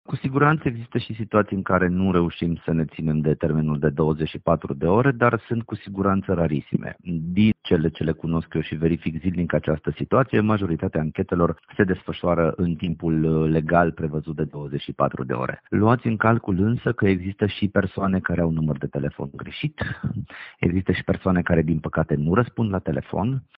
Potrivit directorului DSP, o persoană diagnosticată cu noul coronavirus ar trebui să anunțe contacții direcți, urmând ca aceștia să fie contactați de Direcția de Sănătate Publică: